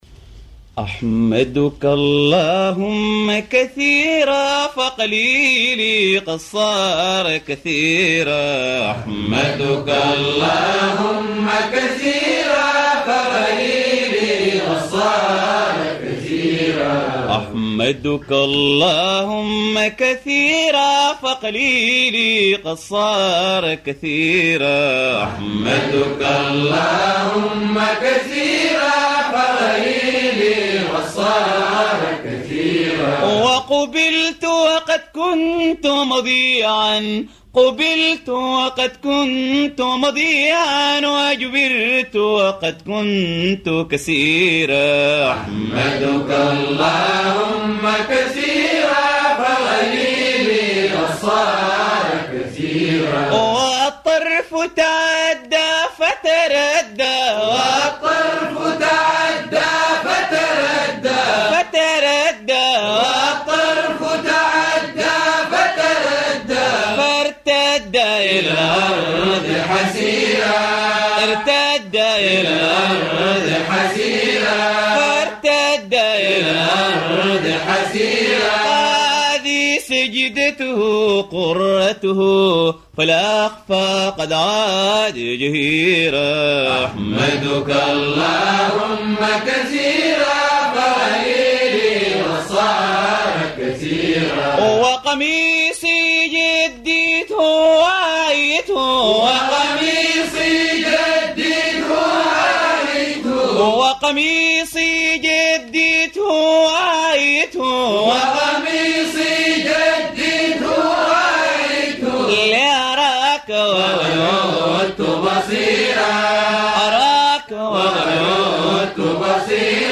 إنشاد
القصائد العرفانية